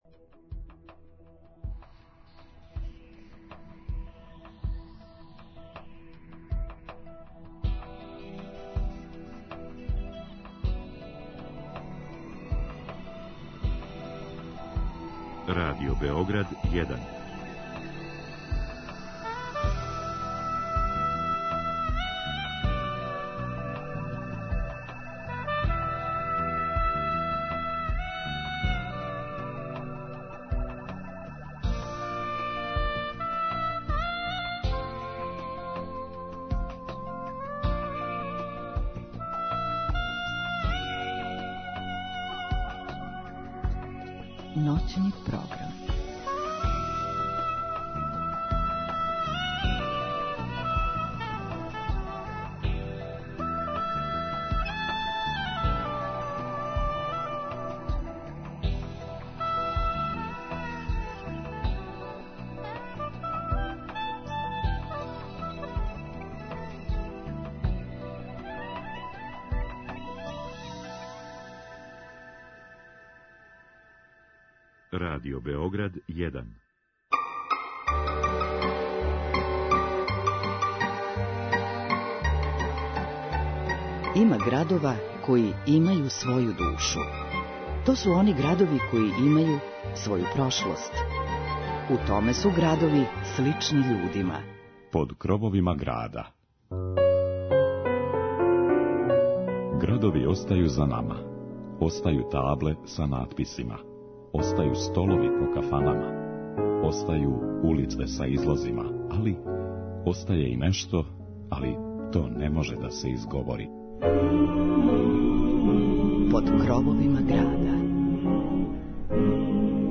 Сомбор има богату и интересантну историју, као и културне знаменитости. И обог петка на суботу, проведите ноћ уз Радио Београд један, звуке тамбурице и причу о Сомбору.